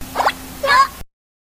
contribs)The Ice Climbers' sound clip when selected with a Wii Remote.